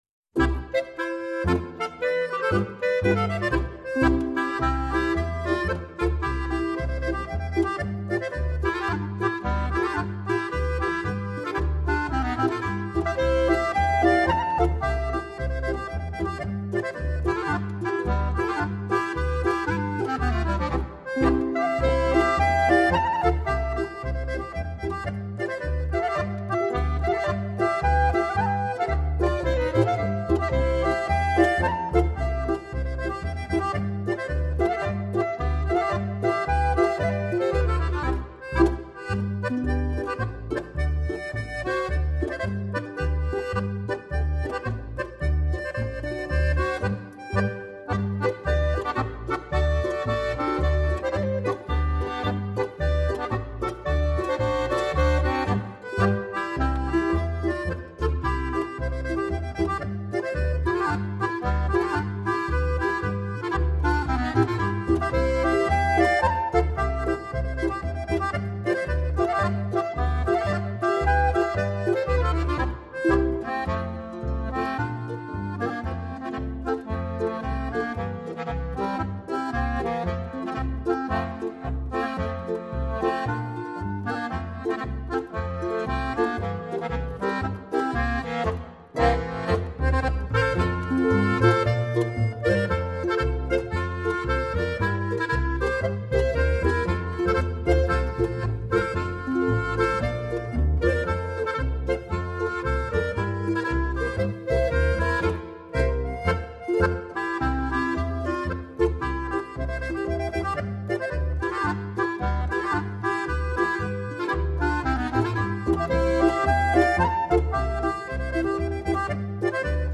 Bayrische Band Musik, Stimmung zünftig, Trio,
• Volksmusik